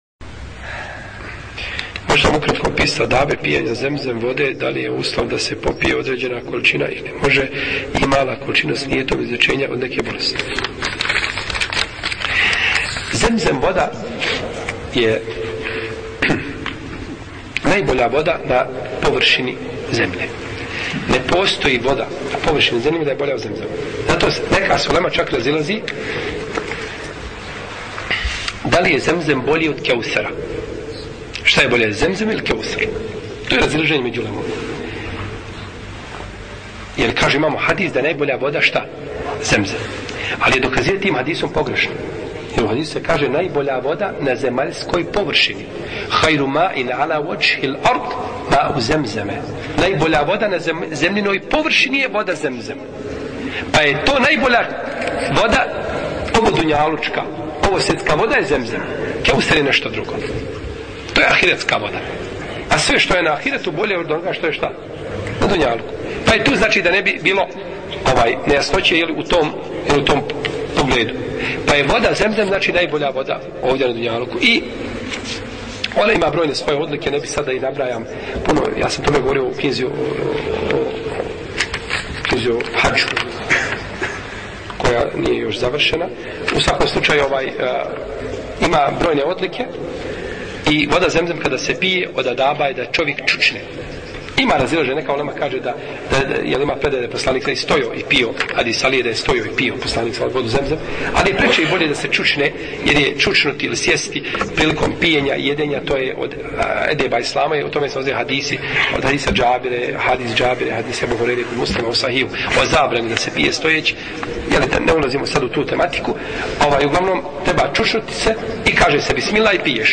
odgovor je u kratkom predavanju na linku ispod: